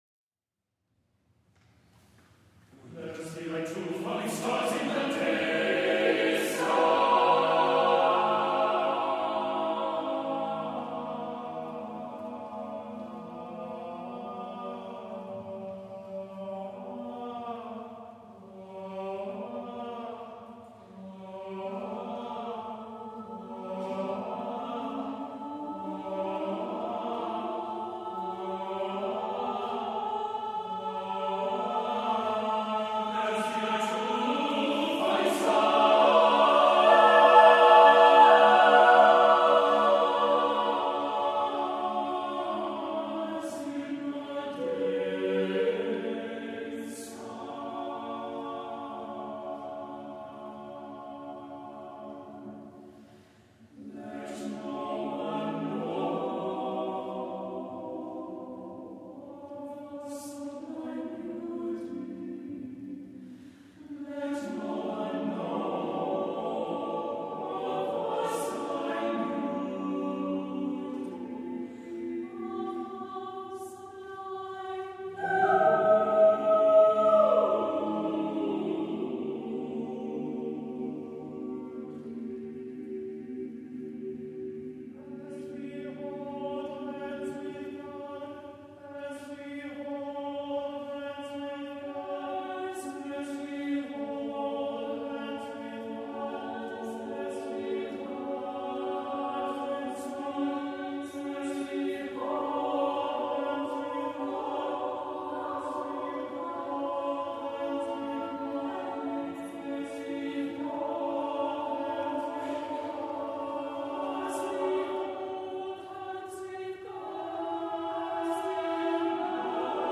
SATB a cappella